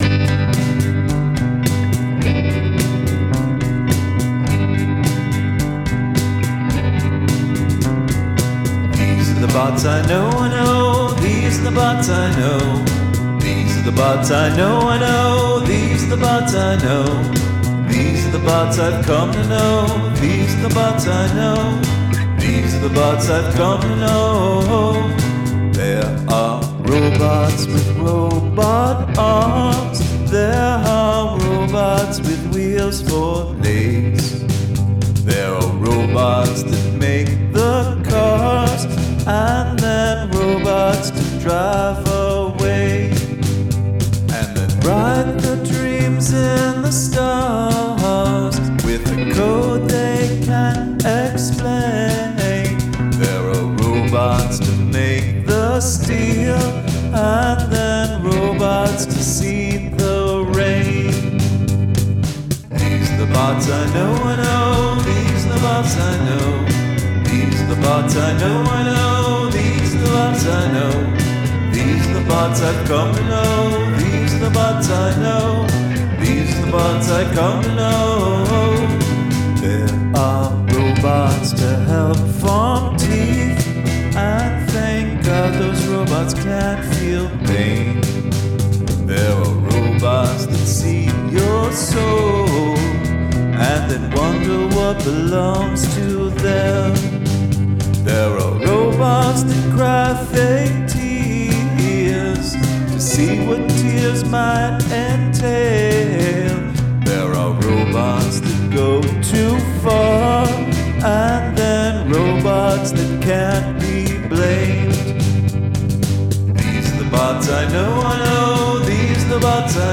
I think this is your best vocal so far too.